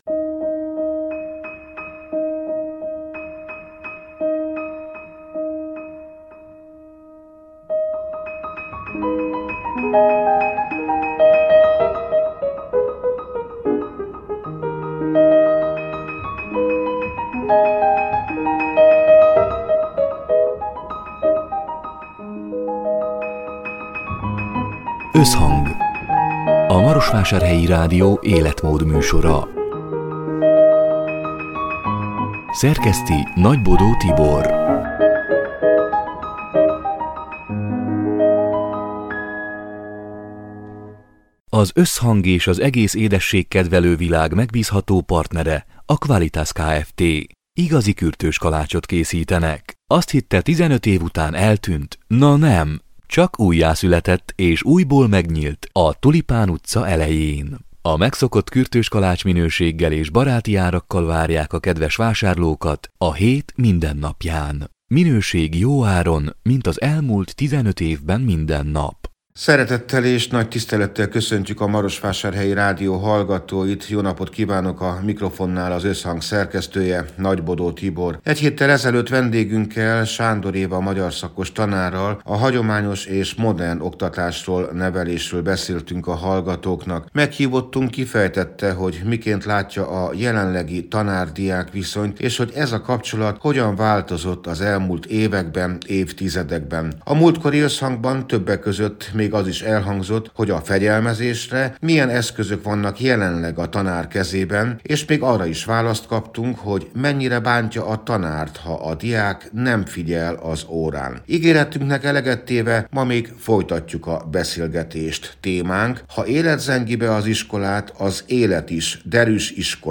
Ígéretünknek eleget téve, szerdán délután még folytatjuk a beszélgetést.